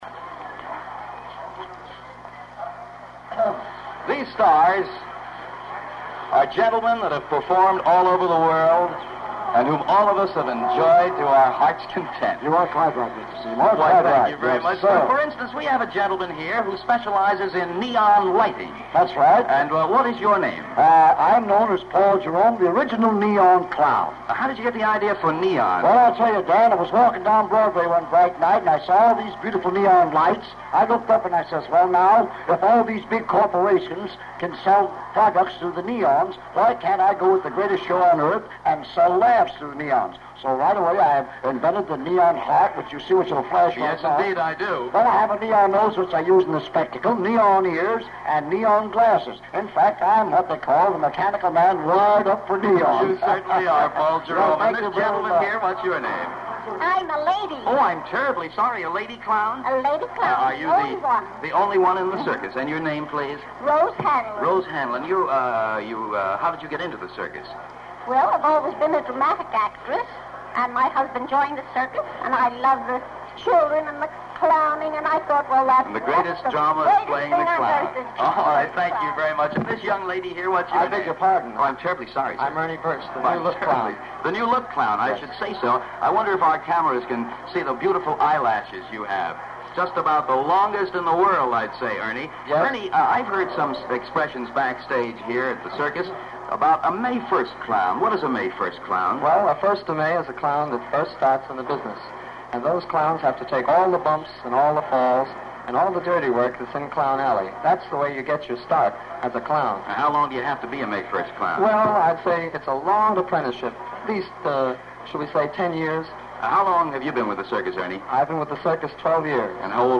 We the People was an American 30-minute talk show aired on CBS television (1948-1949) and then on NBC Television (1949-1952), hosted by Dan Seymour, sponsored by Gulf Oil, and produced by Life magazine.
One of the shows in 1950 featured backstage interviews of Ringling Bros. and Barnum & Bailey personel while at Madison Square Garden, New York City.